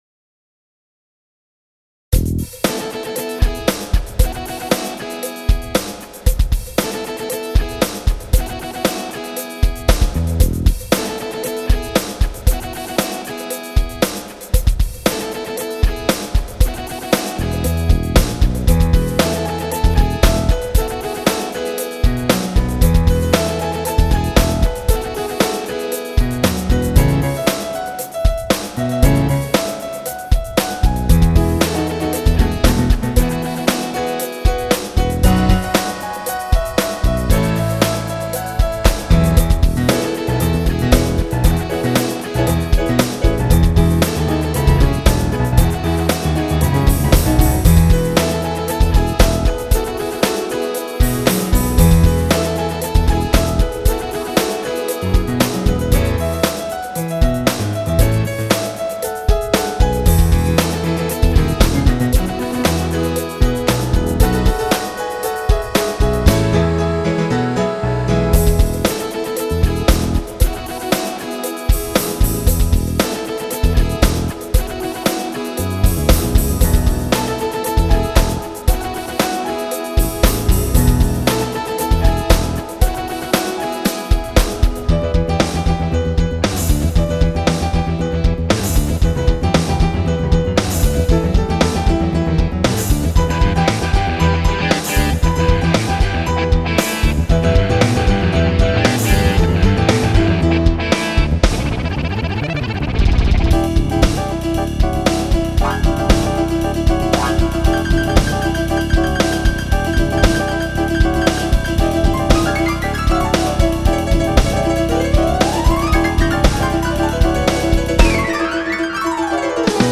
继续打鼓，因为这里缺乏鼓声